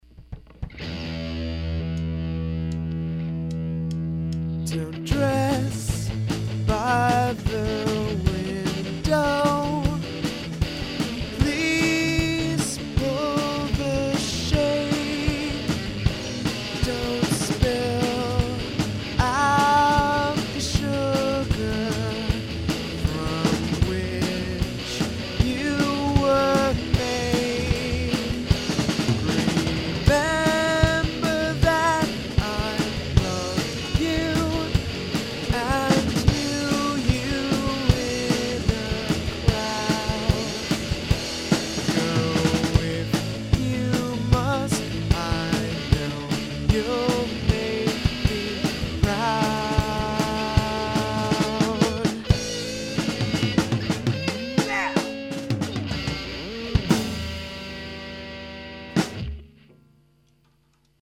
It's linear right now.
So the first change is I-v7.
This current demo kind of makes light of the song. It's one of the more starkly emotional lyrics I've written, and I think I'm afraid of trying to play it straight and somber on this one for that reason.
the version as it is now feels like the climax of the song. i would love to hear a version like what you are considering, with a quiet time through that builds up to this. it wouldn't necessarily need more lyrics at all.